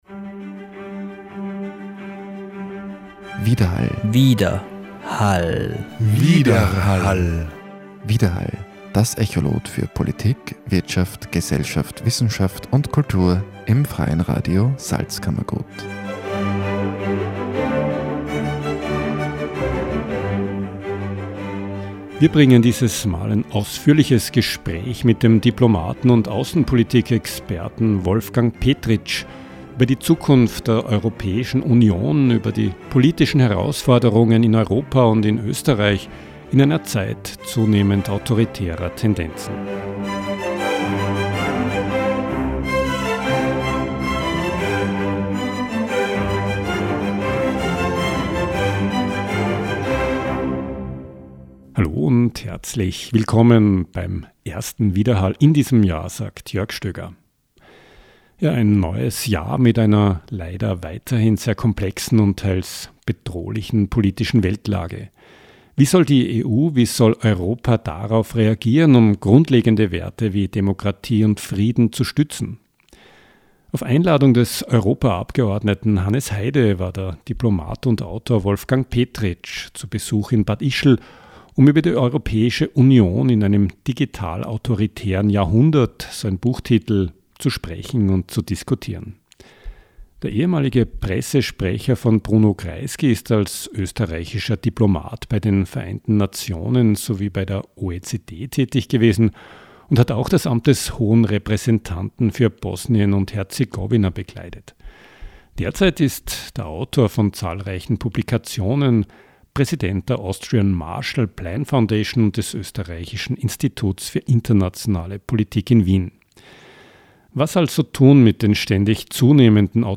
Europa in Zeiten autoritärer Tendenzen. Gespräch mit dem Diplomaten und Außenpolitikexperten Wolfgang Petritsch über die Zukunft der Europäischen Union und die politischen Herausforderungen, Frieden und Demokratie zu stützen.